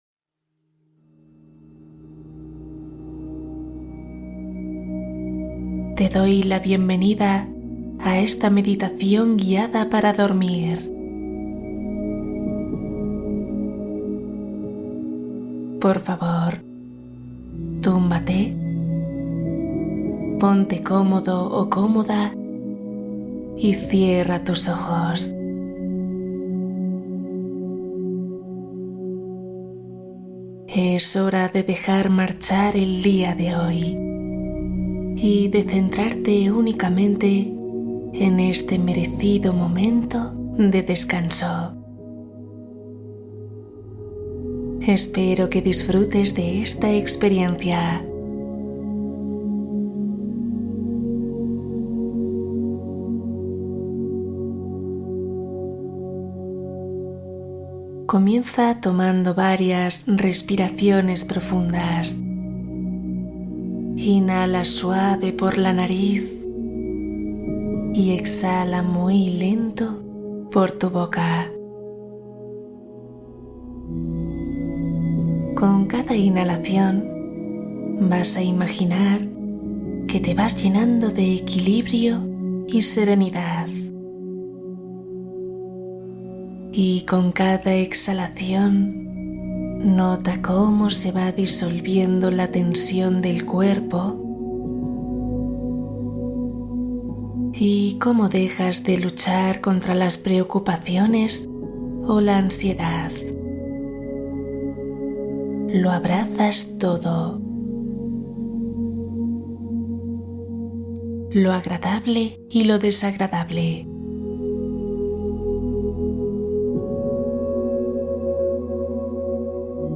Duerme rápido y profundo Relajación para eliminar insomnio